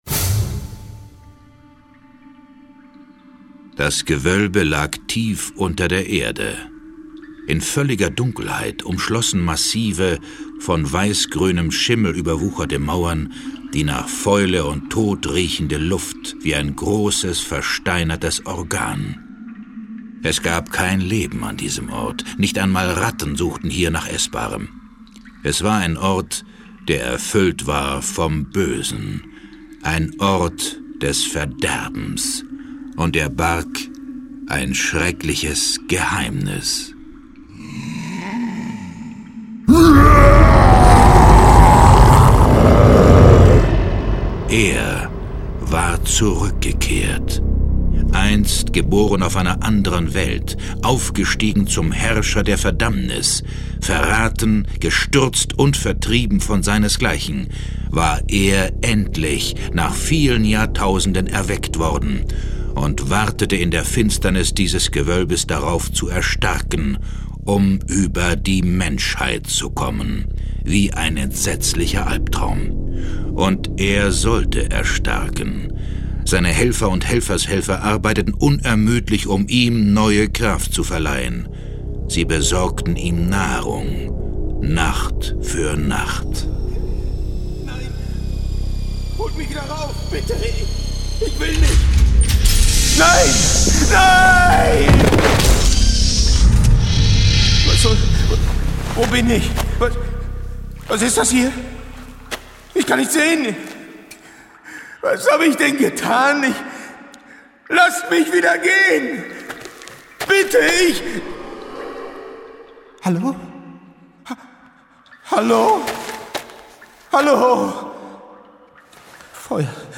John Sinclair - Folge 7 Das Horror-Schloss im Spessart. Hörspiel.